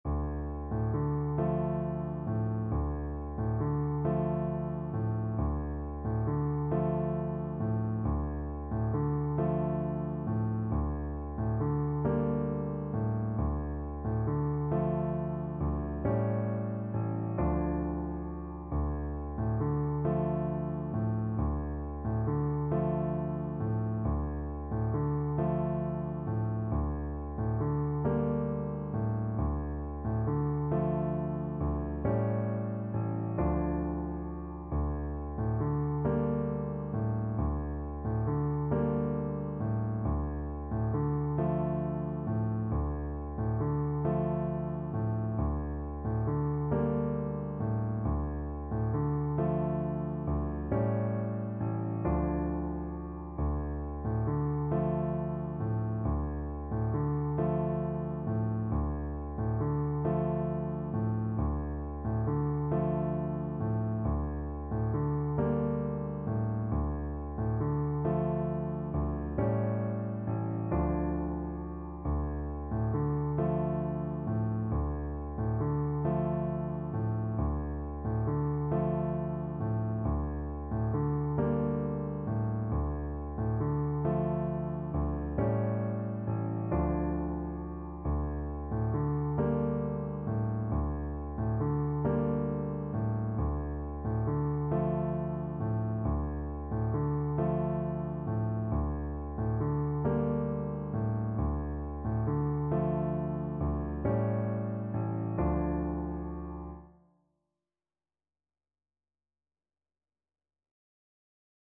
Gently rocking .=c.45